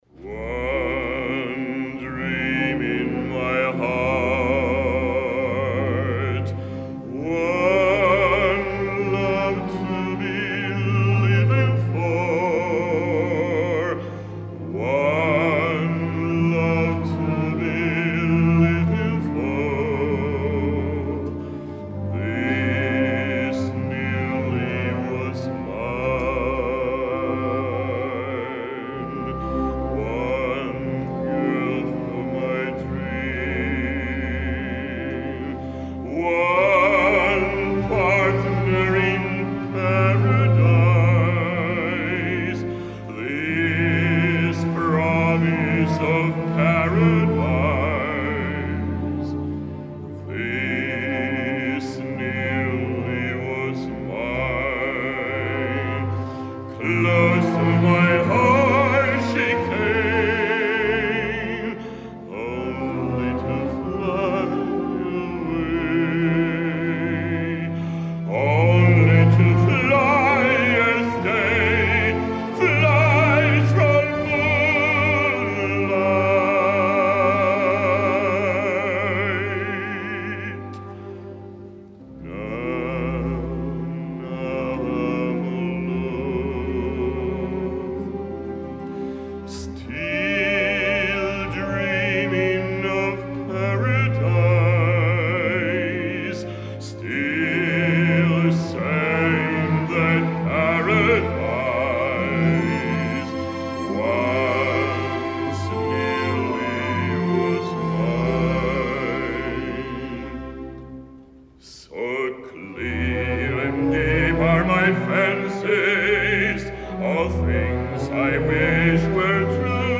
concert semi escenificat